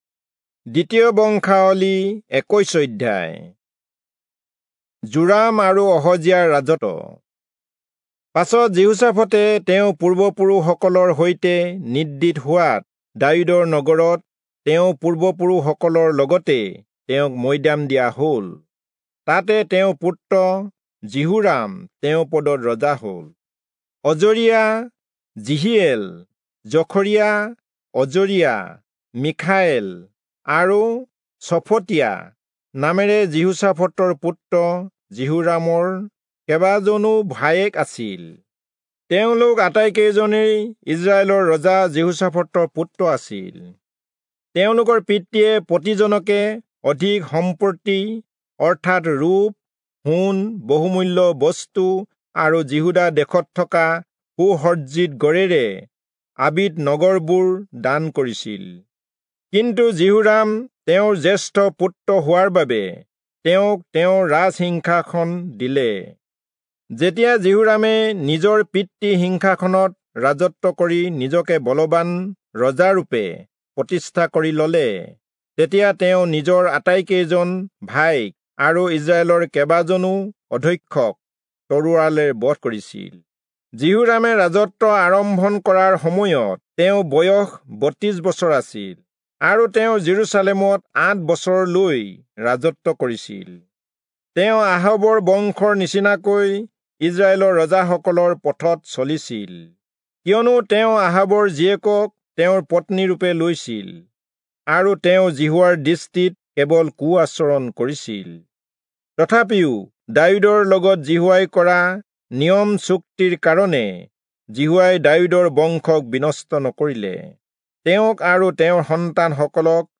Assamese Audio Bible - 2-Chronicles 23 in Mov bible version